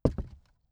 WoodFootsteps
ES_Walk Wood Creaks 13.wav